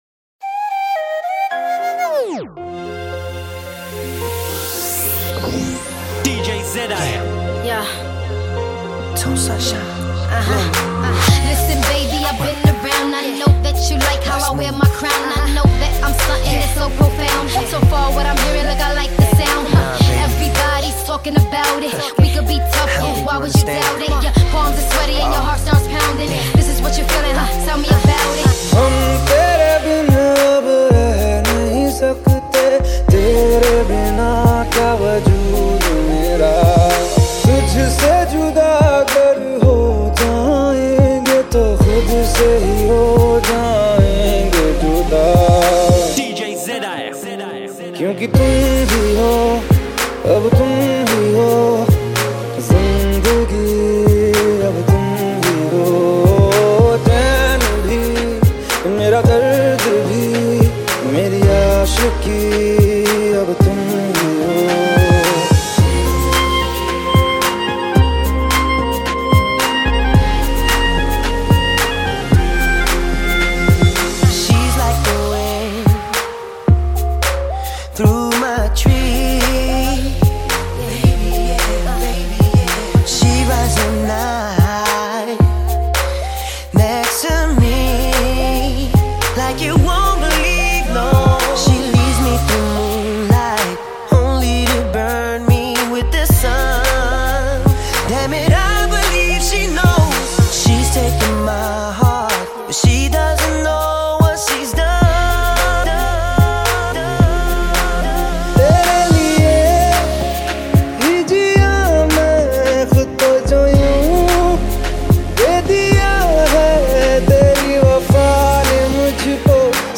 Hip Hop Remix